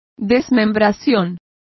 Complete with pronunciation of the translation of dismemberment.